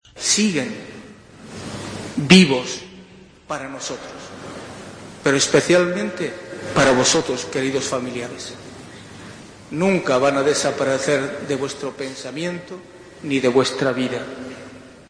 El obispo de la diócesis de Cartagena, José Manuel Lorca Planes en la misa funeral por los cinco jóvenes fallecidos en un accidente de tráfico